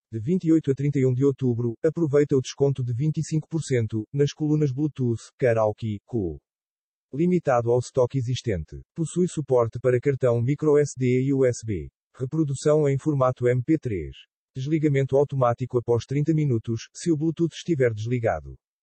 mp3-output-ttsfreedotcom-1.mp3